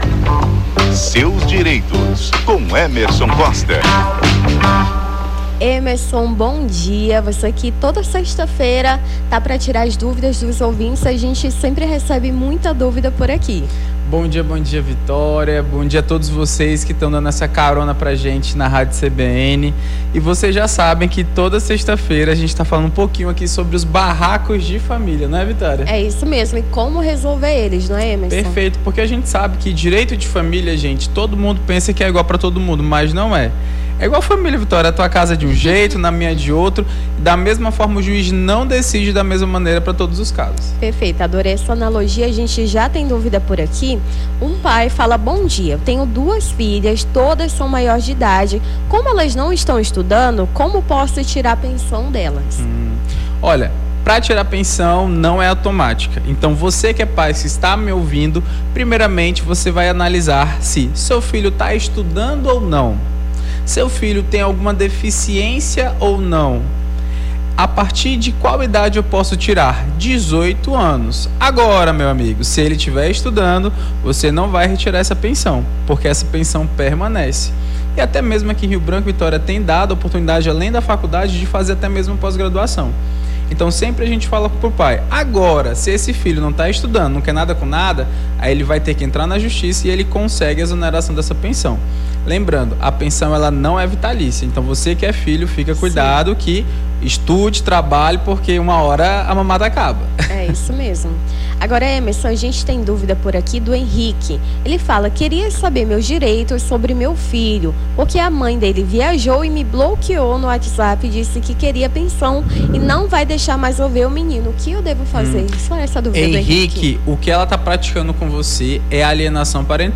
Seus Direitos: advogado esclarece dúvidas dos ouvintes sobre direito de família